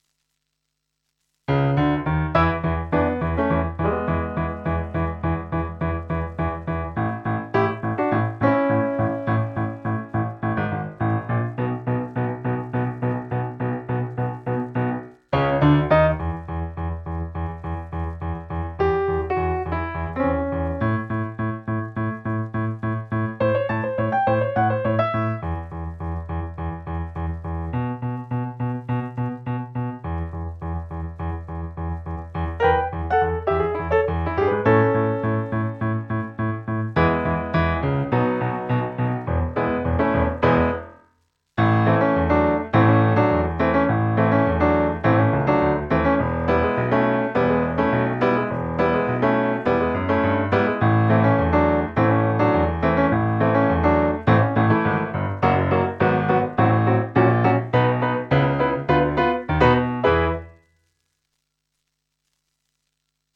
piano track here and sheet music here.